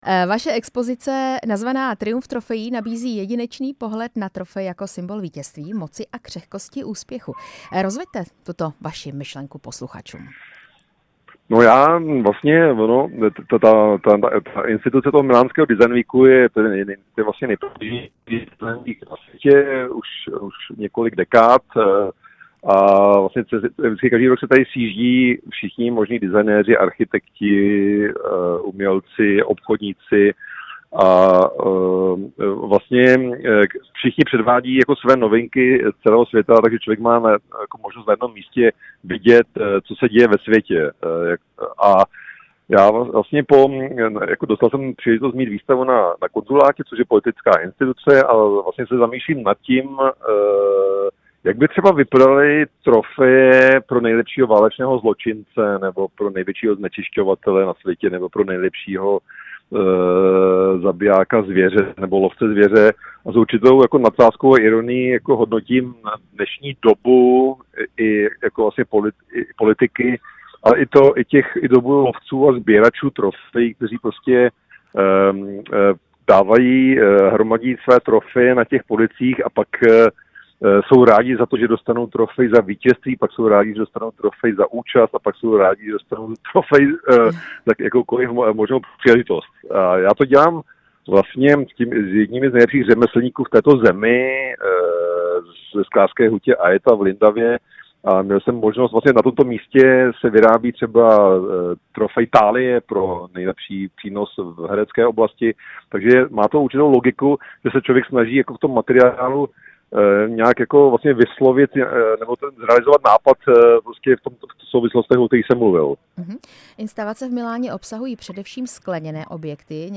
Designér Maxim Velčovský, známý svými ikonickými porcelánovými a skleněnými objekty, představil v rámci milánského týdne designu svou nejnovější expozici nazvanou „Triumf trofejí“. S Maximem Velčovským jsme se krátce před vernisáží spojili ve vysílání Rádia Prostor.
Rozhovor s designérem Maximem Velčovským